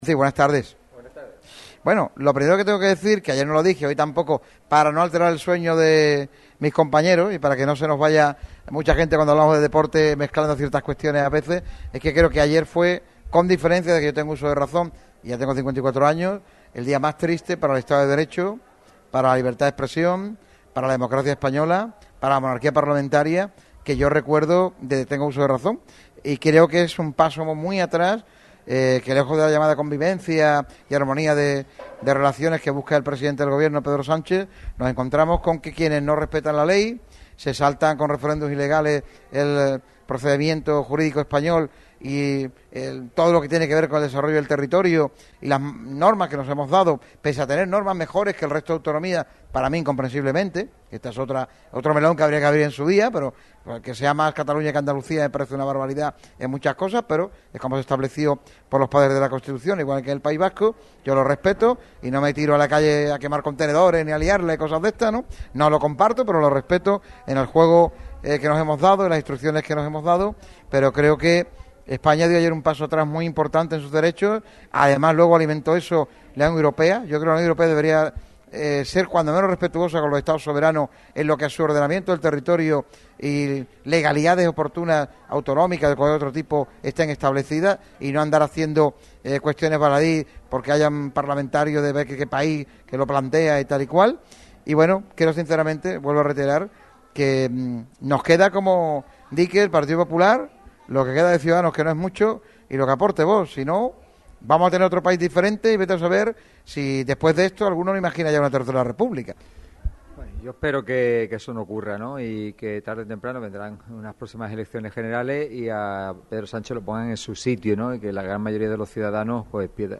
El alcalde de Rincón de la Victoria y presidente de la Diputación de Málaga, Francisco Salado, ha pasado por los micrófonos de Radio Marca Málaga en nuestro paso por el Añoreta Golf Resort, y ha tratado diversos temas a lo largo del encuentro que se ha producido en el .